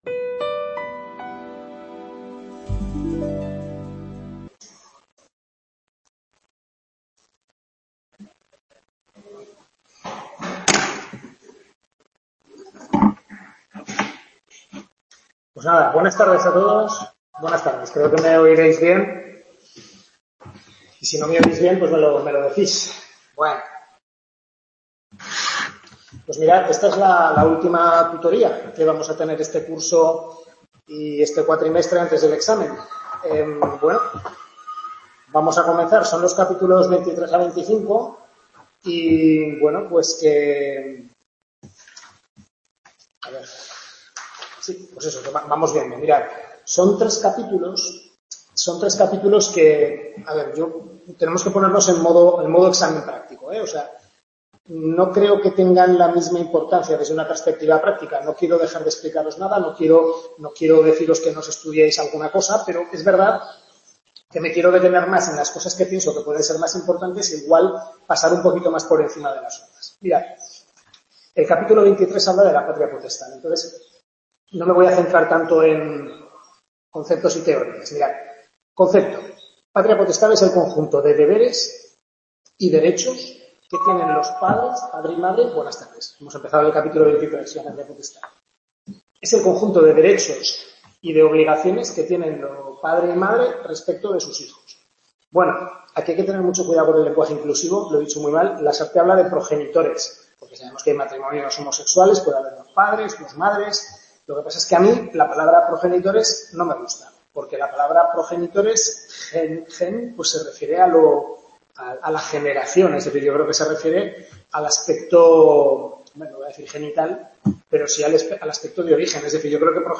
Tutoría de Civil I (Familia), centro de Calatayud, capítulos 23-25 del Manual